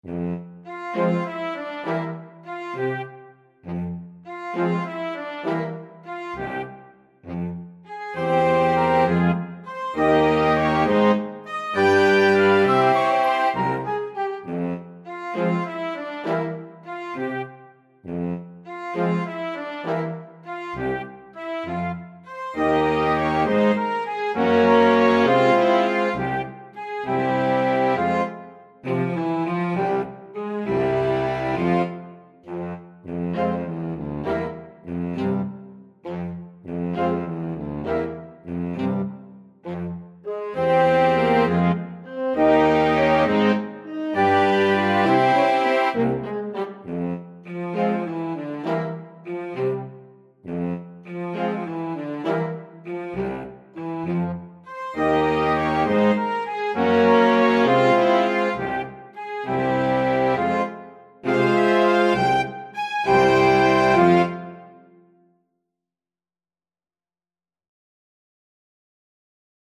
Free Sheet music for Flexible Mixed Ensemble - 5 Players
F minor (Sounding Pitch) (View more F minor Music for Flexible Mixed Ensemble - 5 Players )
Moderato
6/8 (View more 6/8 Music)
Classical (View more Classical Flexible Mixed Ensemble - 5 Players Music)